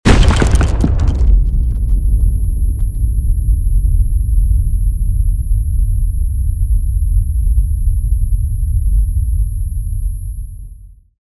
Звуки контузии, потери слуха
На этой странице собрана коллекция звуковых эффектов контузии и временной потери слуха. Эти реалистичные аудиофайлы имитируют ощущение оглушения после взрыва, мощного удара или резкого громкого звука.